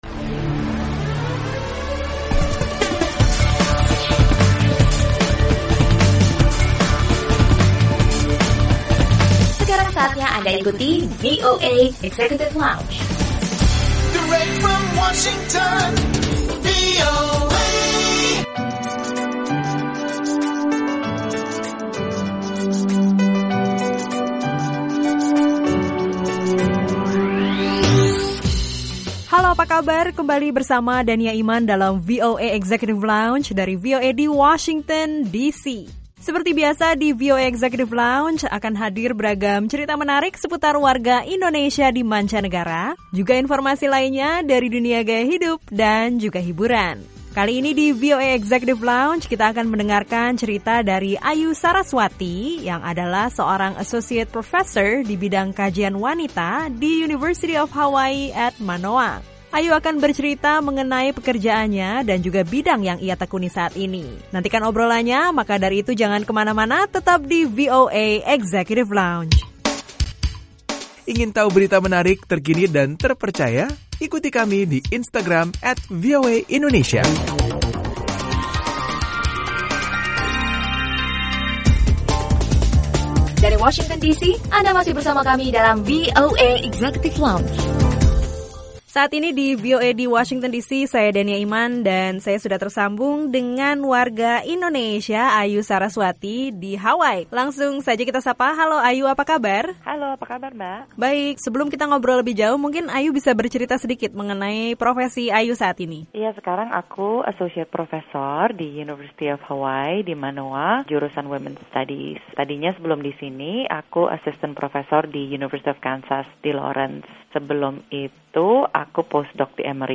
Kali ini ada obrolan